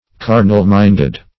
Carnal-minded \Car"nal-mind`ed\, a. Worldly-minded.
carnal-minded.mp3